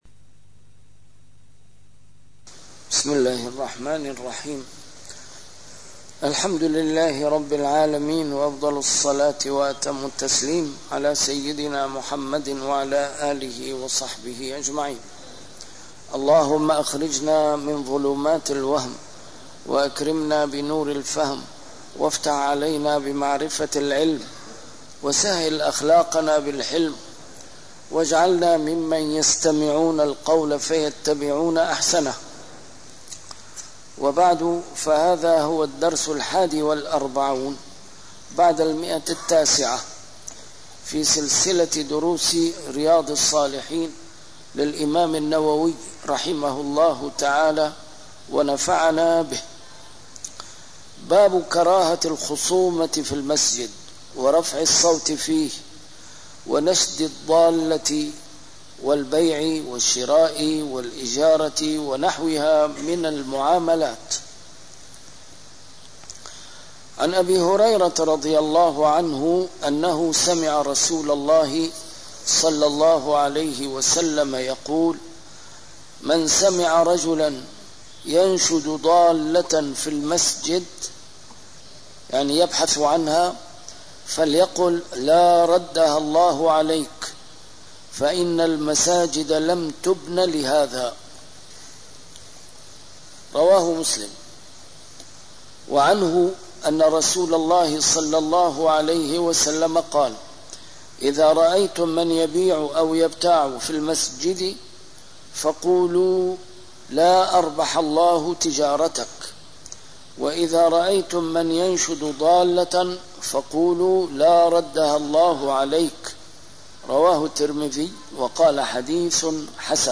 A MARTYR SCHOLAR: IMAM MUHAMMAD SAEED RAMADAN AL-BOUTI - الدروس العلمية - شرح كتاب رياض الصالحين - 941- شرح رياض الصالحين: كراهة الخصومة في المسجد - نهي من أكل ثوماً ونحوه عن دخول المسجد